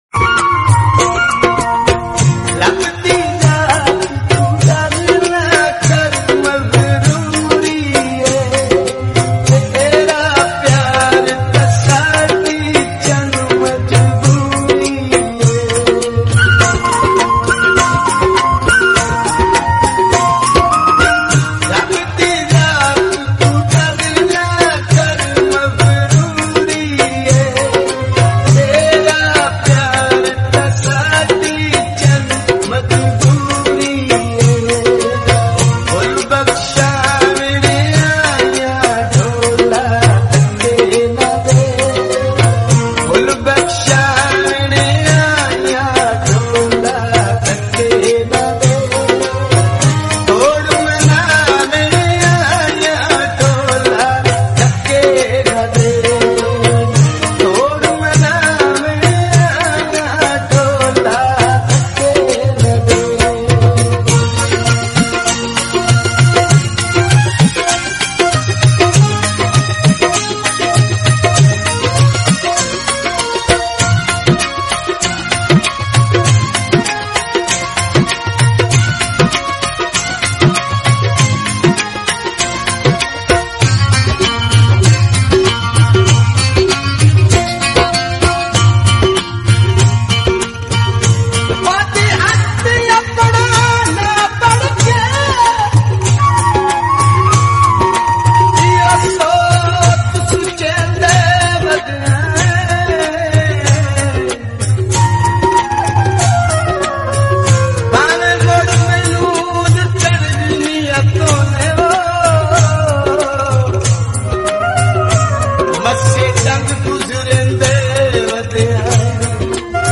TRANDING SAD SONG 🥰🎶 ( ՏᒪOᗯ & ᖇᗴᐯᗴᖇᗷ )